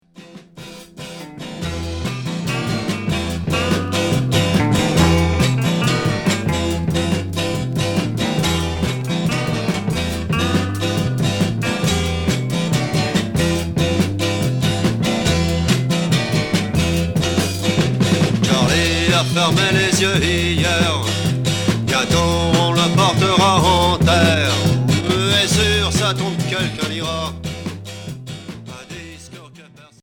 Beat folk